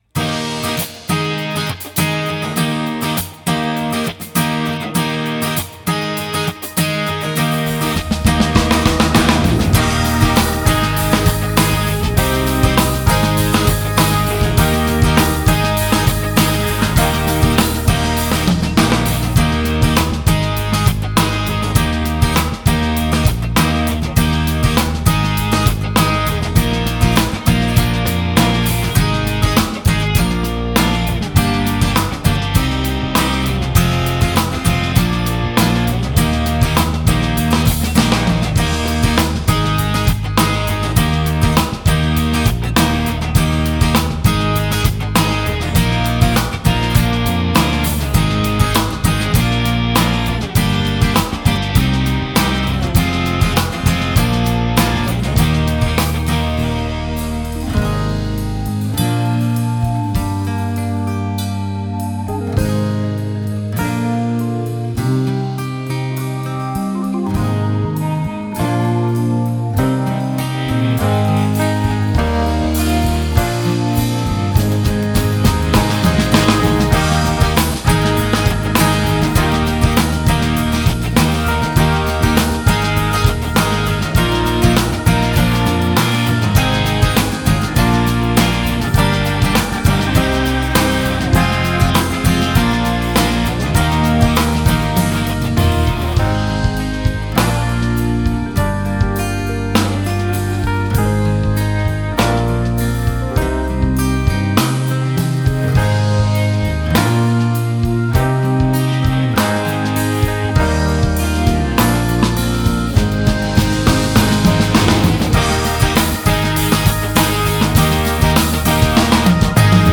mp3 伴奏音樂